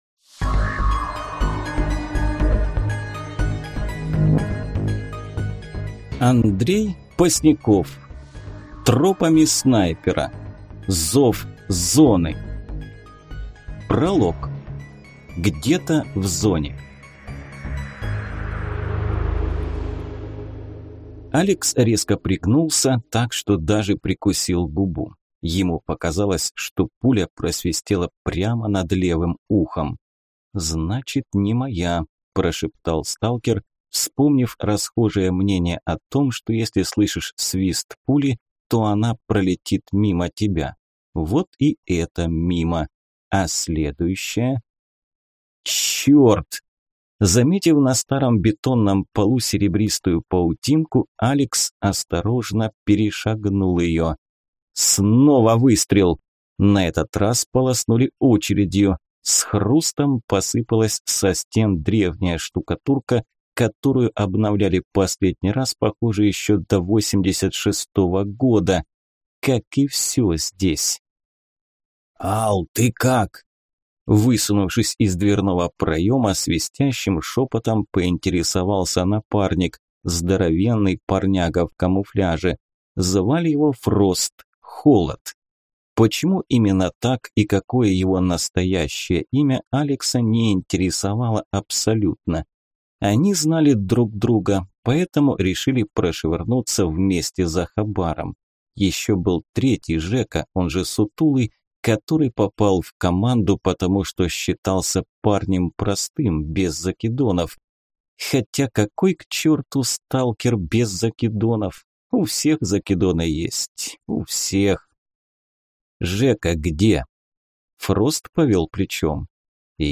Аудиокнига Тропами Снайпера. Зов Зоны | Библиотека аудиокниг